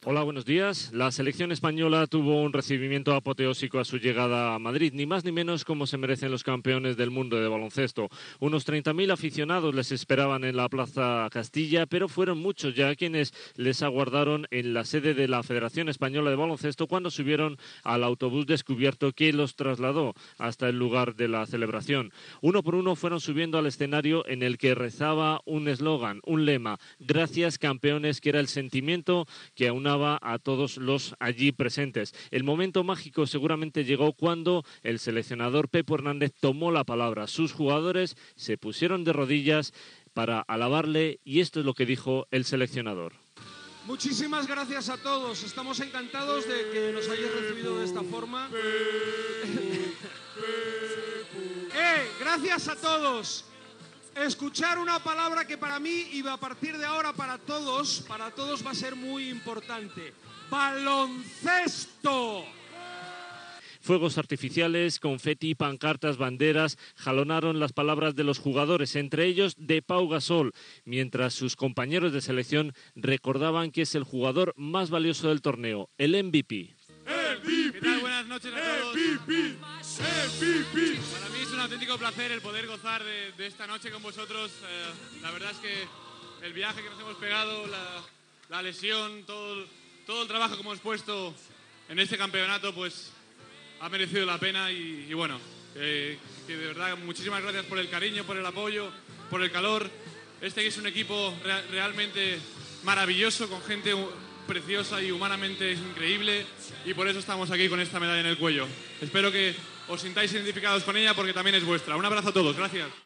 Recepció de la selecció espanyola de bàsquet masculí a Madrid, el dia anterior, després haver-se proclamat campiona del món, paraules de l'entrenador Pepo Hernández i del jugador Pau Gasol
Esportiu
Extret del programa "El sonido de la historia", emès per Radio 5 Todo Noticias el 8 de setembre de 2012